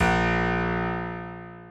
Harpsicord